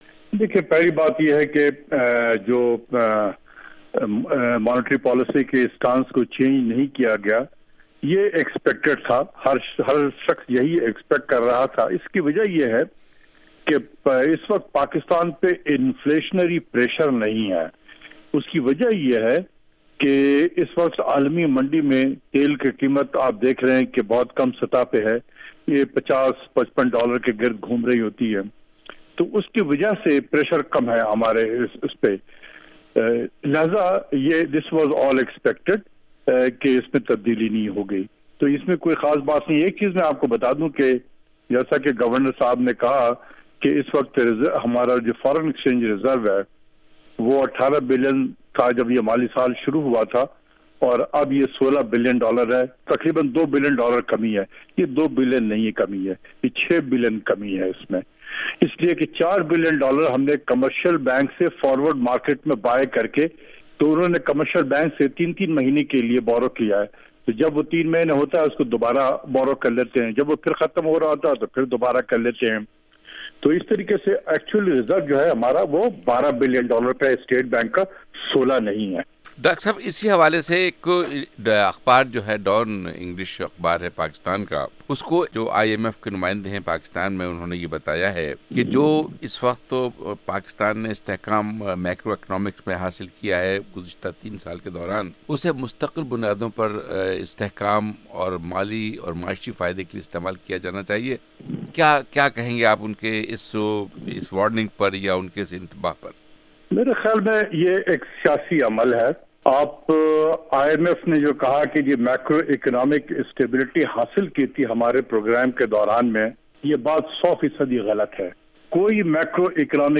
JR discussion: Pakistan monetary policy and IMF